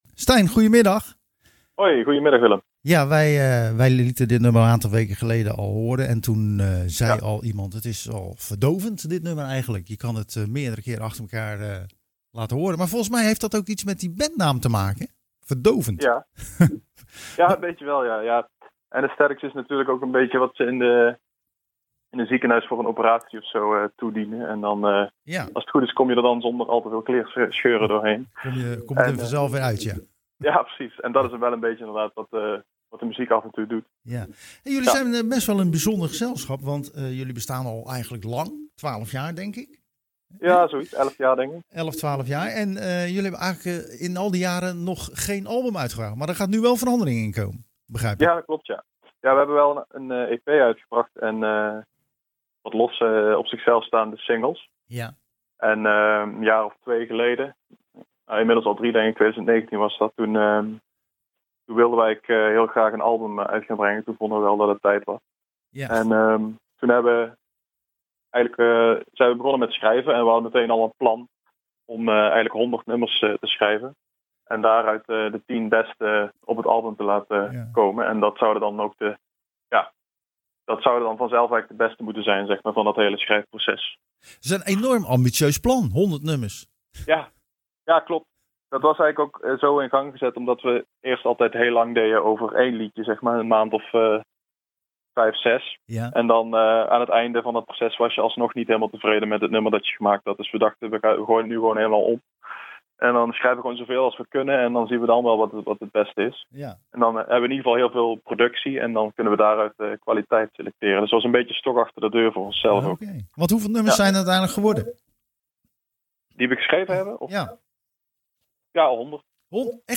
Tijdens het programma Zwaardvis spraken we met het Limburgse kwartet The Anaesthetics. De band bestaat al ruim 10 jaar en komt eindelijk met een debuutalbum genaamd '1080'.